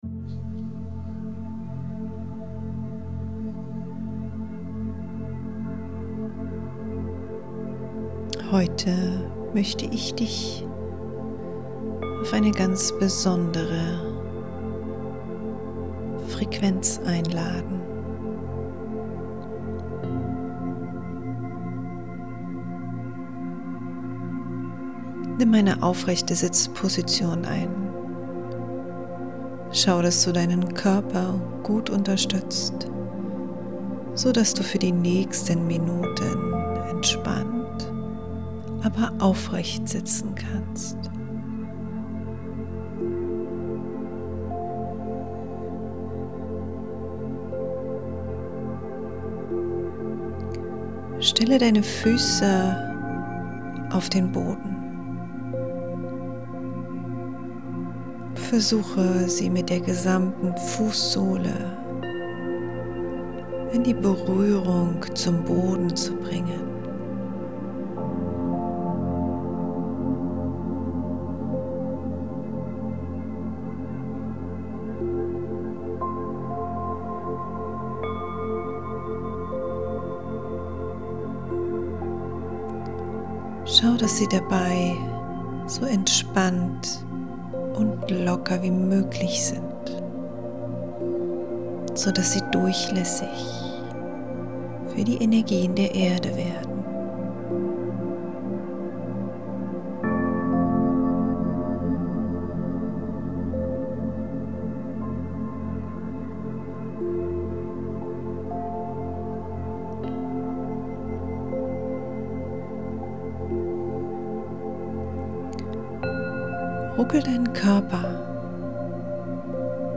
Ich wünsche dir eine lichtvolle Zeit mit meinen Worten, meiner Stimme und der Frequenz der Meditation.
Die-Energie-der-Erde-Full-Meditation.mp3